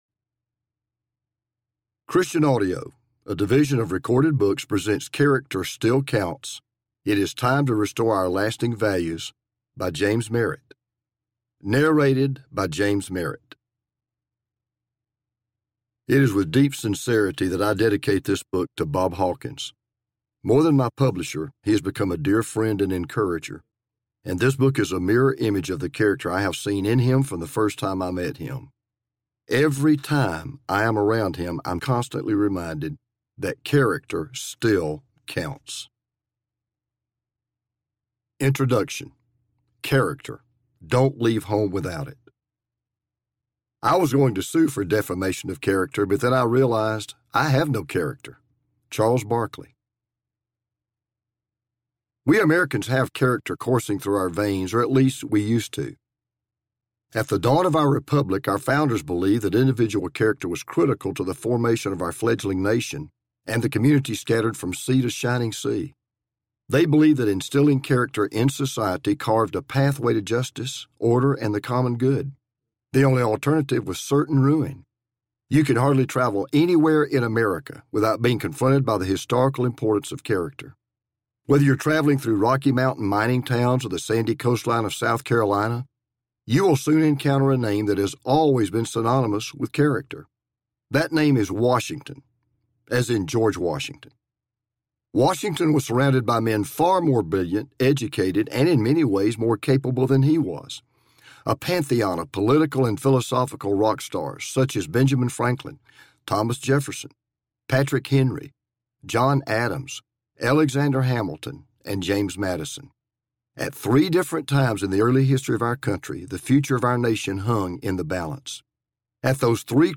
Character Still Counts Audiobook
7.2 Hrs. – Unabridged